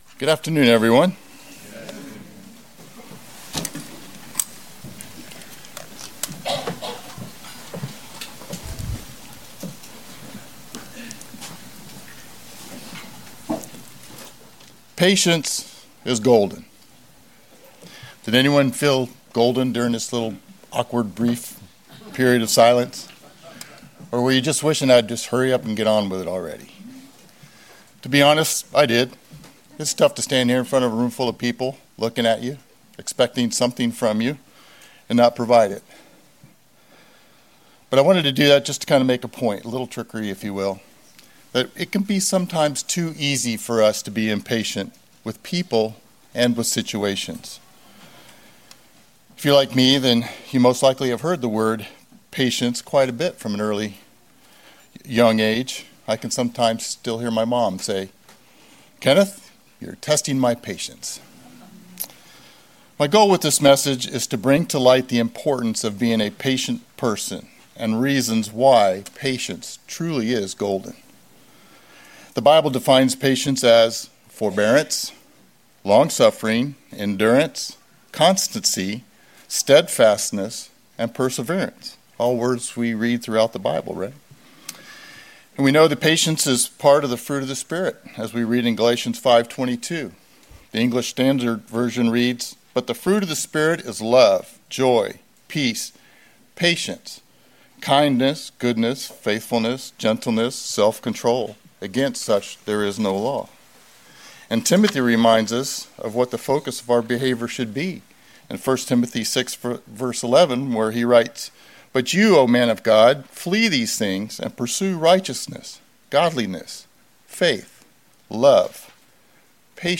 Sermons
Given in Cincinnati East, OH